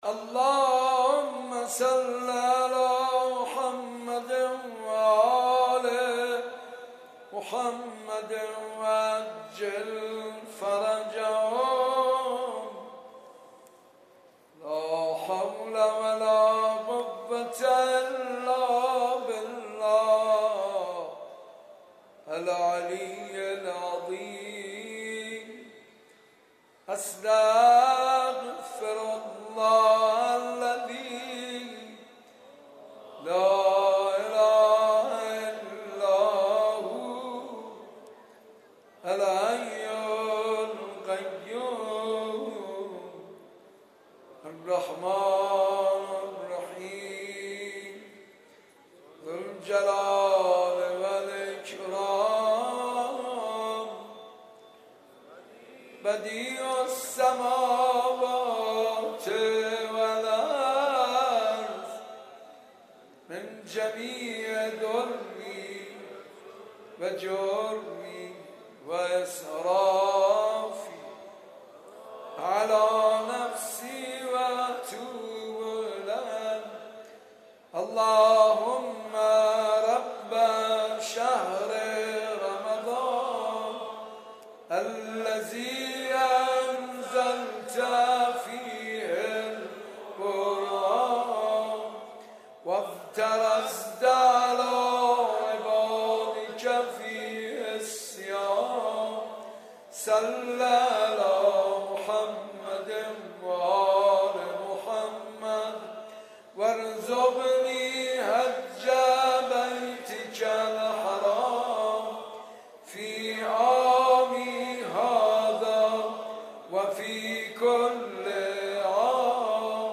ترتیل جزء پانزدهم قرآن کریم با صدای استاد پرهیزکار